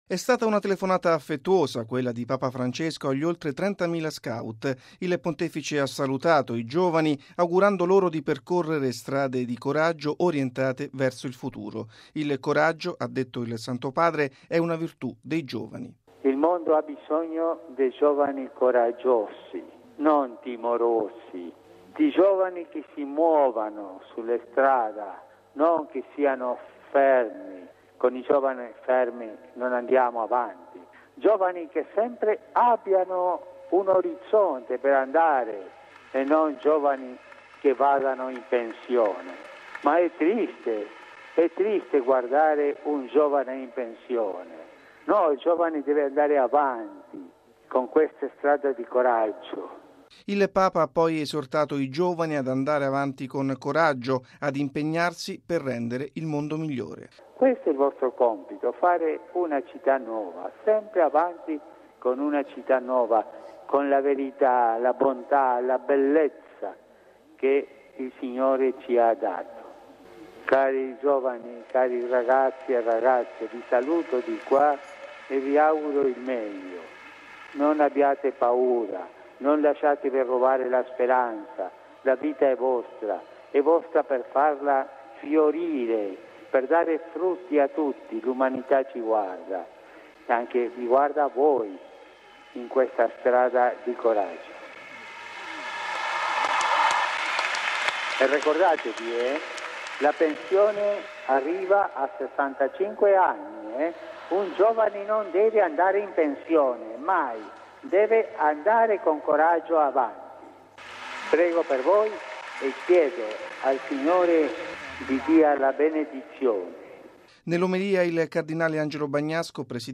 E’ quanto ha detto Papa Francesco salutando in collegamento telefonico oltre 30 mila giovani scout che hanno partecipato alla Santa Messa presieduta dal cardinale Angelo Bagnasco, presidente della Conferenza episcopale italiana, in occasione della conclusione a San Rossore della Route nazionale Agesci.